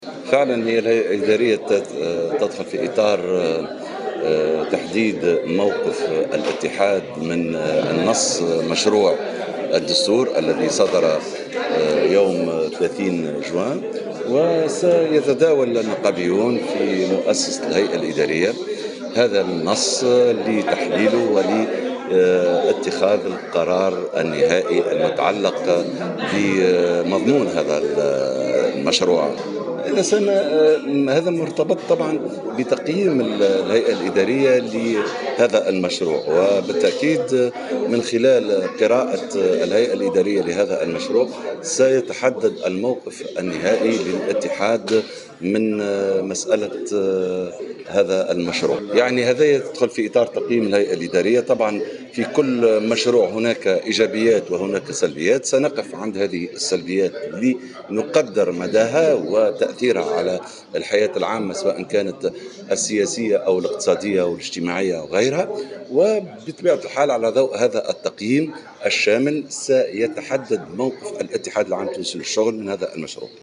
قال الأمين العام المساعد بالاتحاد العام التونسي للشغل، سمير الشفي، في تصريح لمراسلة الجوهرة "اف ام"، اليوم السبت، إن الهيئة الادارية للمنظمة الشغيلة المنعقدة اليوم السبت في الحمامات، ستنظر في تحديد موقف الاتحاد من نص مشروع الدستور الجديد، وتحليله وتقييمه قبل اتخاذ المنظمة لموقفها النهائي منه.